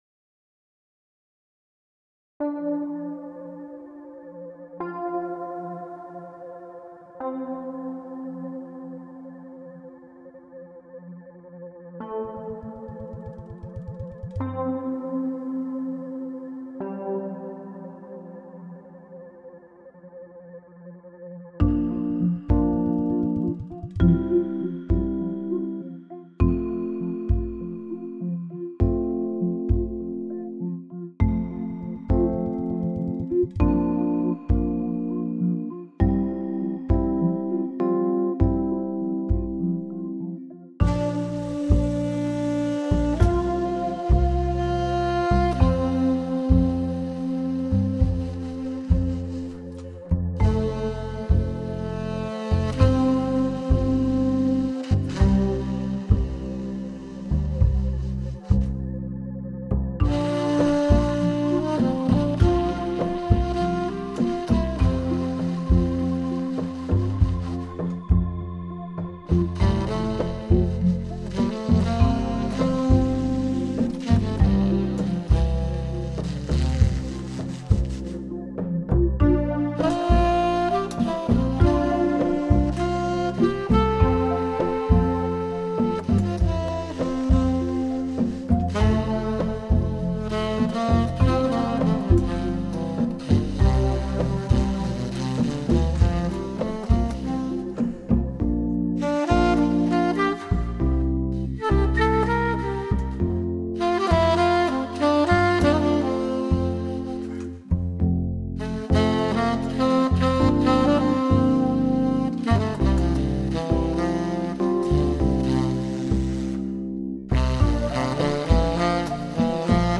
Instrumentalstück mit Tenorsaxophon